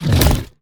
Sfx_creature_pinnacarid_bite_01.ogg